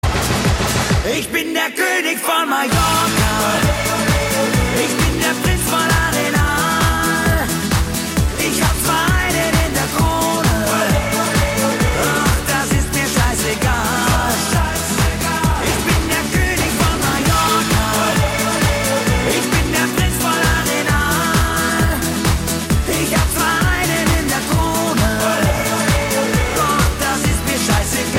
Tonart: F-Dur
Besetzung: Blasorchester